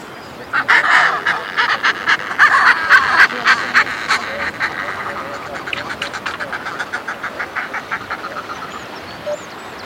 フルマカモメ
【分類】 ミズナギドリ目 ミズナギドリ科 フルマカモメ属 フルマカモメ 【分布】北海道(冬鳥)、本州(冬鳥)、四国(迷鳥:徳島)、沖縄(迷鳥)、南千島(冬鳥)、伊豆諸島(迷鳥:八丈島) 【生息環境】冬に北海道、本州北部の海上に生息 北海道北部・東部では夏にも飛来 【全長】49cm 【主な食べ物】魚、イカ 【鳴き声】地鳴き 【聞きなし】「ガガガガ」「グワッグワッ」